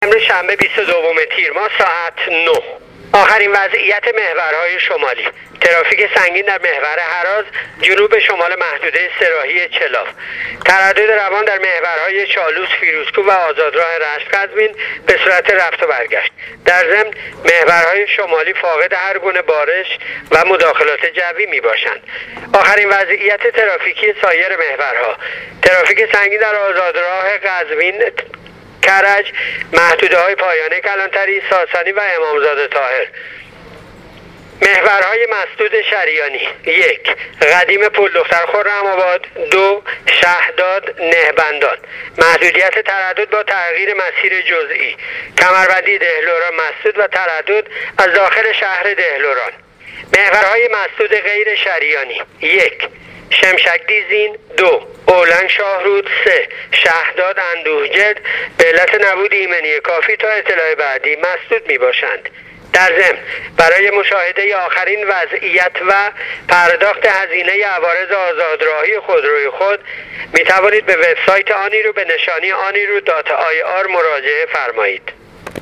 گزارش رادیو اینترنتی وزارت راه و شهرسازی از آخرین وضعیت‌ ترافیکی راه‌های کشور تا ساعت ۹ بیست و دوم تیرماه/ ترافیک سنگین در مسیر جنوب به شمال محور هراز